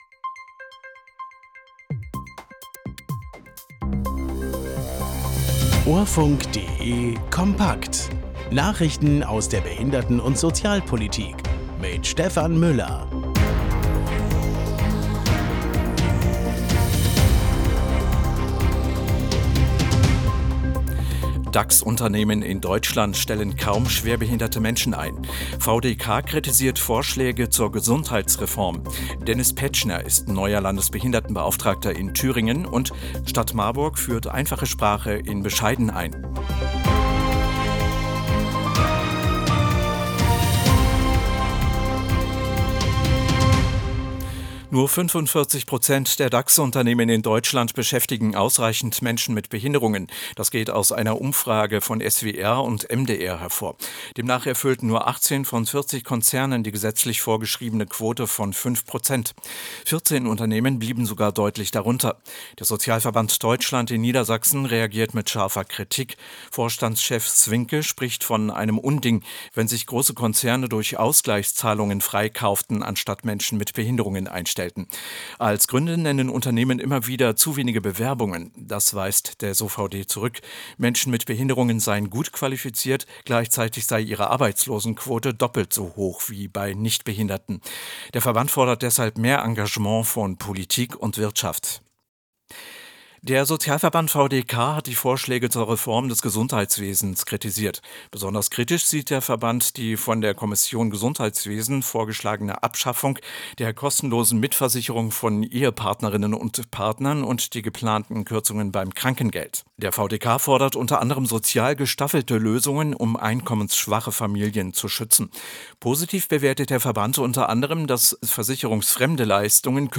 Nachrichten aus der Behinderten- und Sozialpolitik vom 01.04.2026